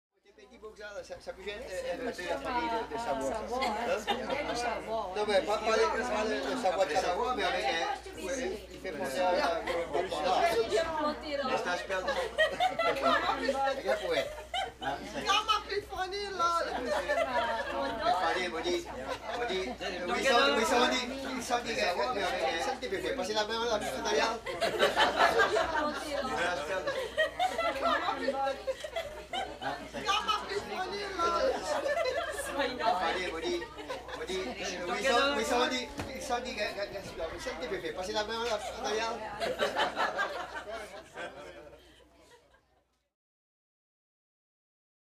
Walla, Cajun | Sneak On The Lot
French Cajun Walla With Pointed Lines And Laughter